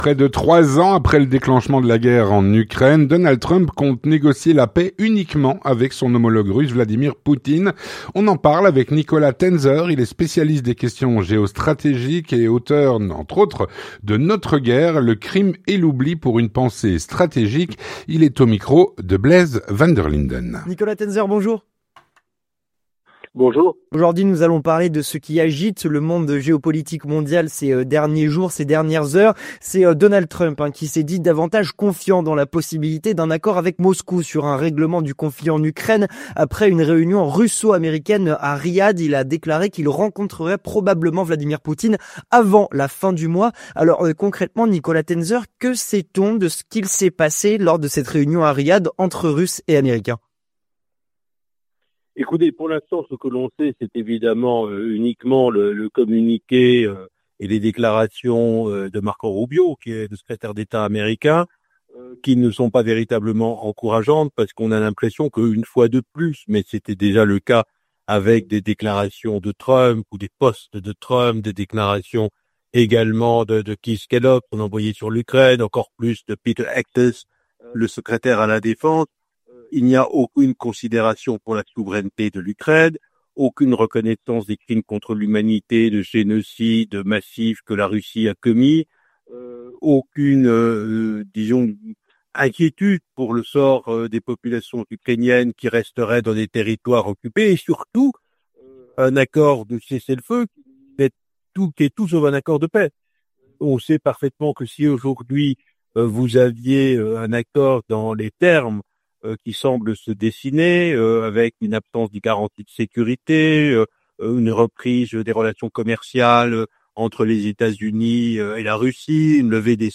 L'entretien du 18H - Donald Trump compte négocier la paix uniquement avec Vladimir Poutine.